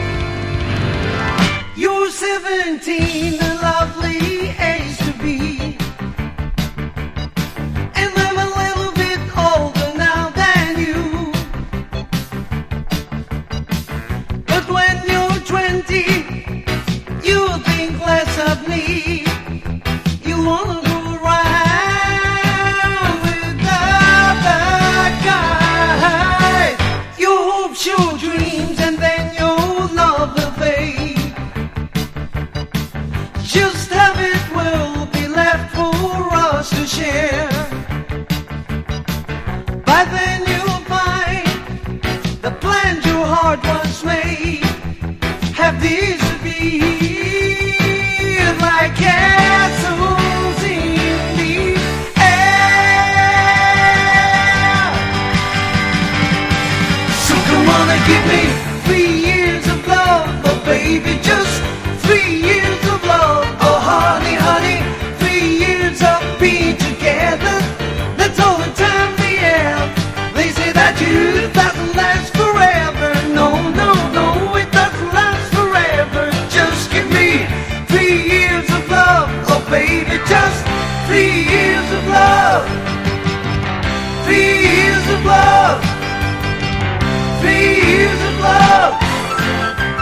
様々ジャンルをブレンドしポップでとても聴き易い曲が集められたノン・コンセプト・アルバム！